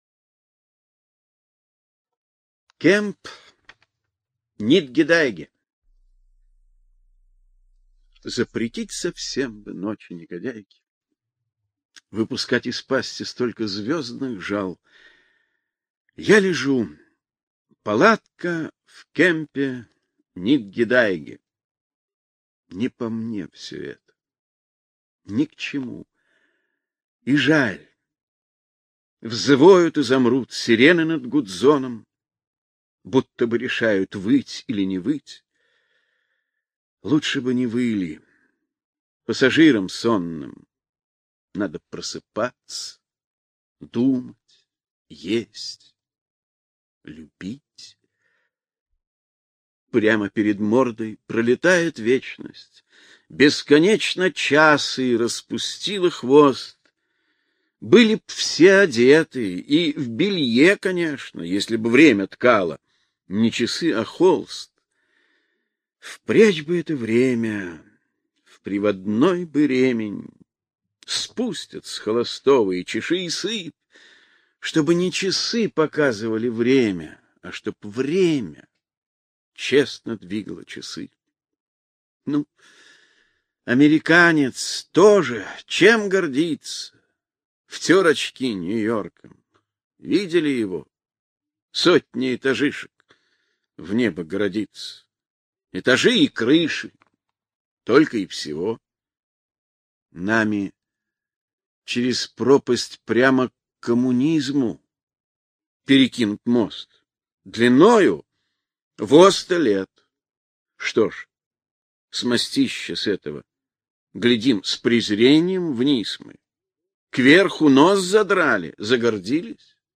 2. «Владимир Маяковский – Читает Всеволод Аксёнов (190 – Кемп Нит гедайге» /